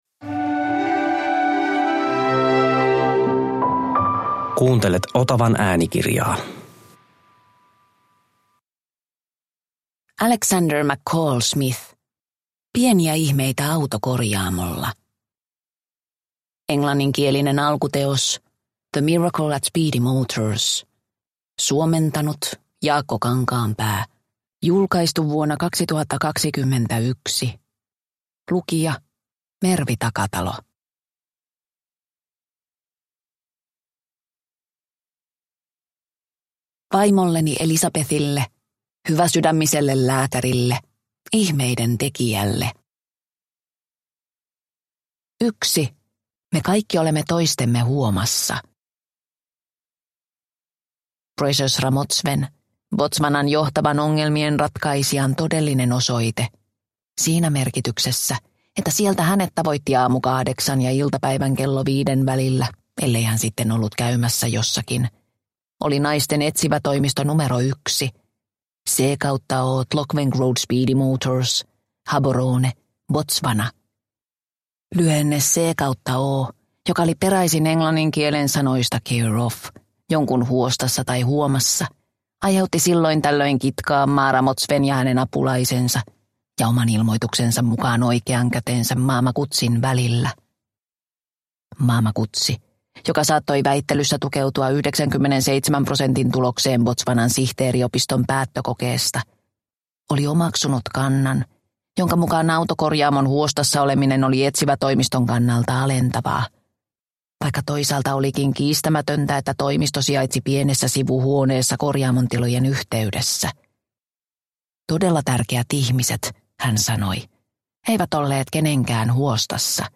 Pieniä ihmeitä autokorjaamolla – Ljudbok – Laddas ner